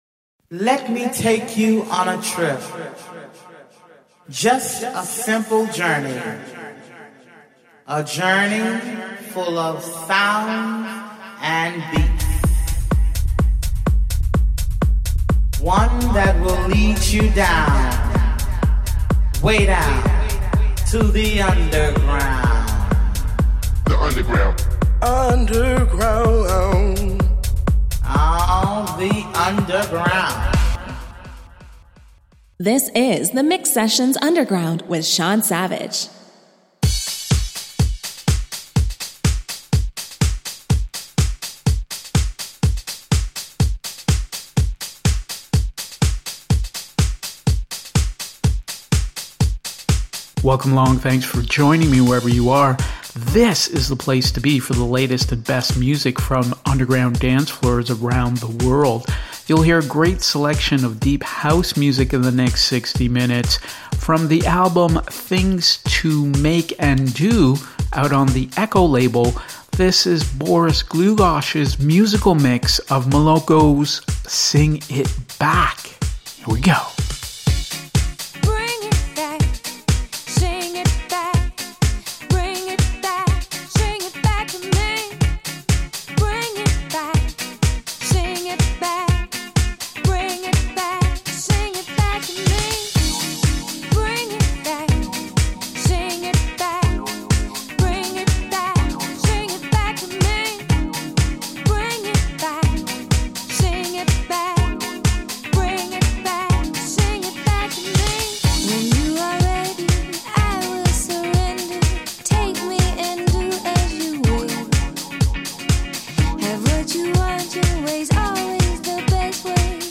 Underground Deep House and Techno